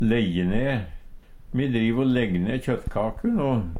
lejje ne - Numedalsmål (en-US)